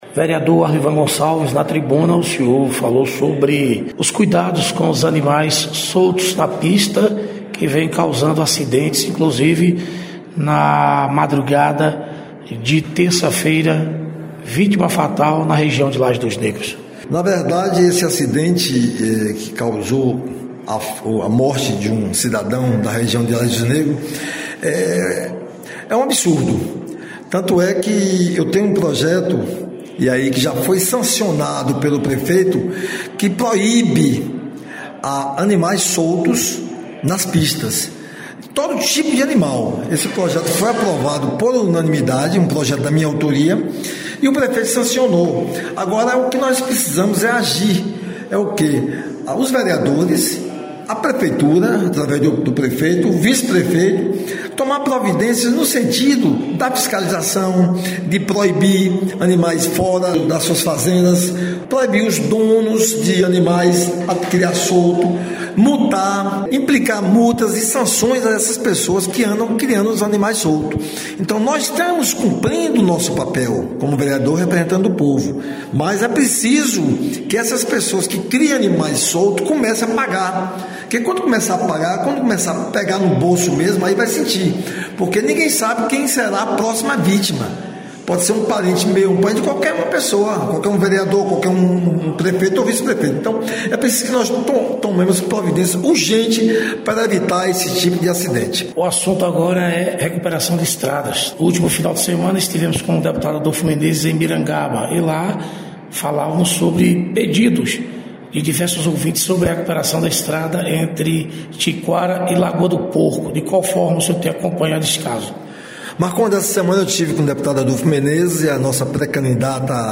Reportagem com vereadores de Campo Formoso.